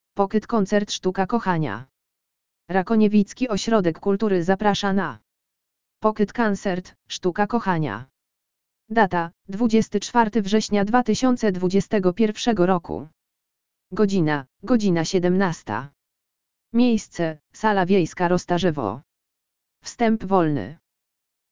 audio_lektor_pocket_concert_sztuka_kochania.mp3